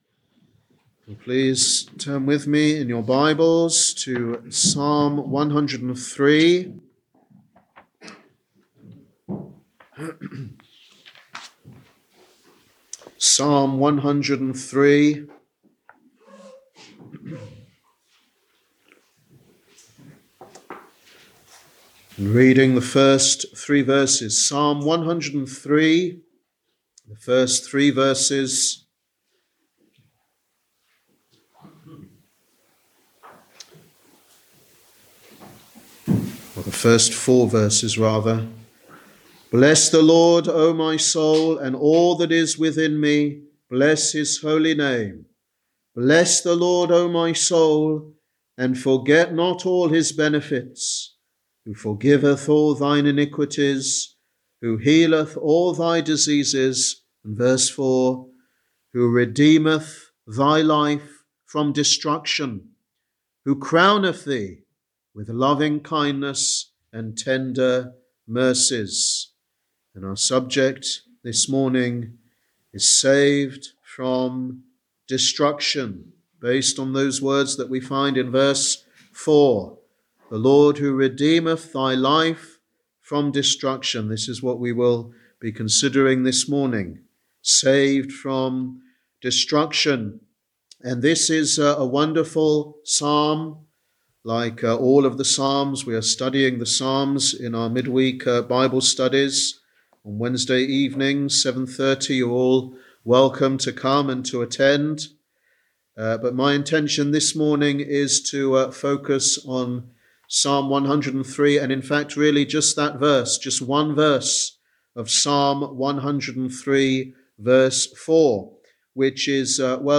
Sunday Evangelistic Service